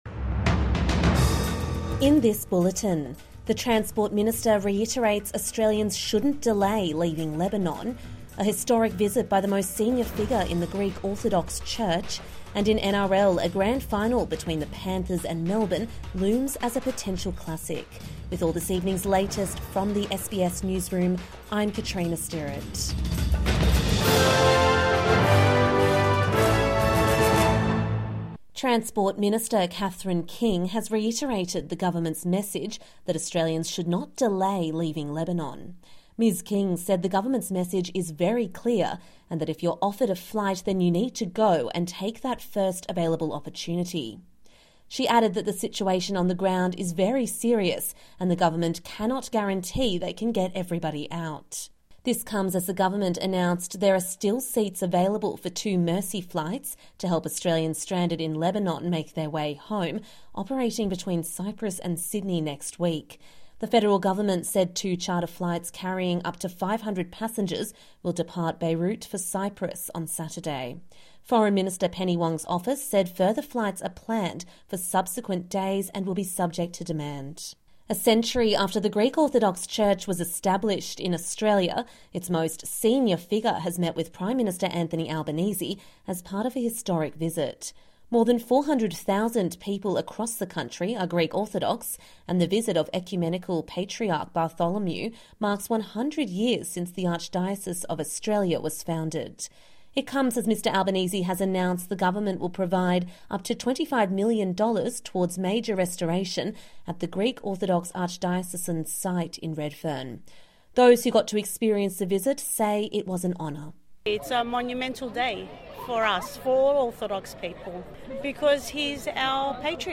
Evening News Bulletin 5 October 2024